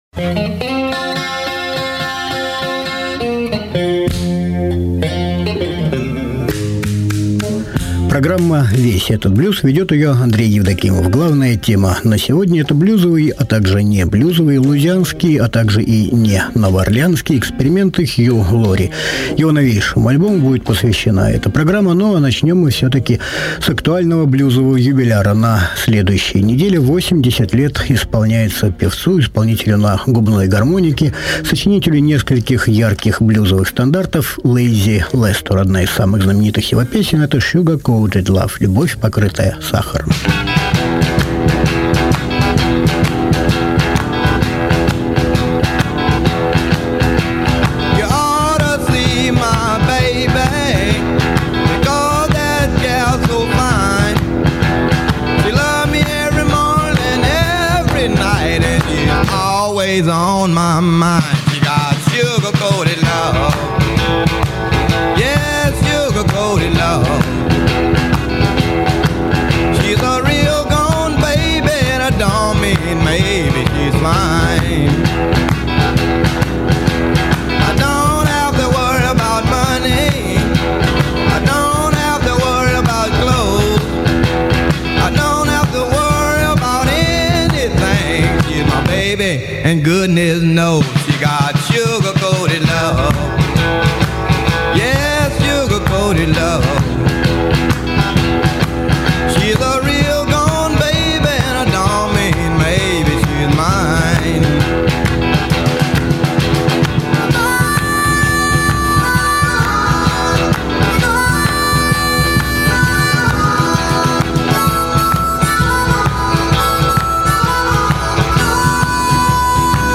блюзовый скрипач, вокалист и аранжировщик